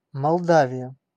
Ääntäminen
Synonyymit Молдова Ääntäminen Tuntematon aksentti: IPA: /mɐlˈdavʲɪjə/ Haettu sana löytyi näillä lähdekielillä: venäjä Käännös Ääninäyte Erisnimet 1.